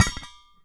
metal_small_impact_shake_01.wav